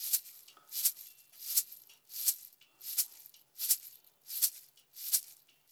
SWC SHAKER.wav